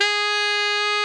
Index of /90_sSampleCDs/Giga Samples Collection/Sax/GR8 SAXES FF
TNR FFF-G#4.wav